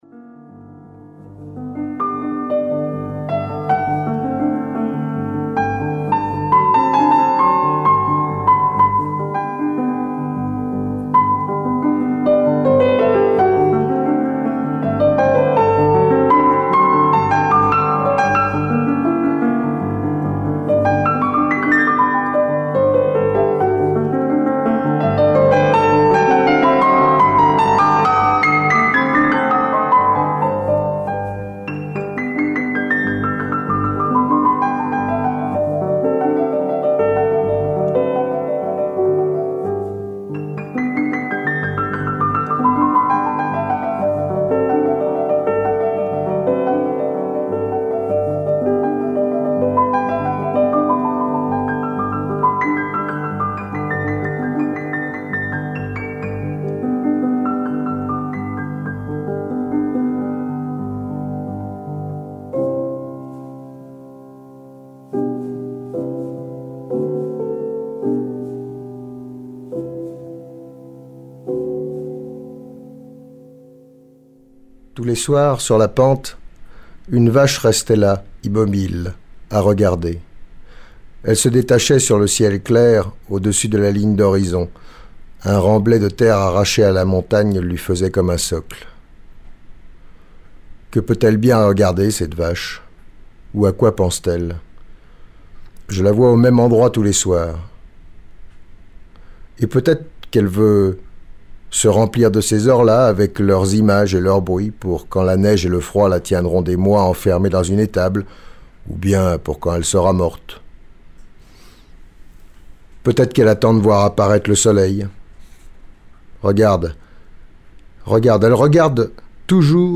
Radio Royans vous livre un pan de notre période contemporaine avec les témoignages de celles et ceux qui ont pratiqué le Vercors du bas vers le haut et vice-versa.
Réalisé à l’occasion d’une journée d’étude patrimoniale à St Gervais et St André en Royans portée le CPIE Vercors (centre permanent d’initiatives pour l’environnement) – sept 2001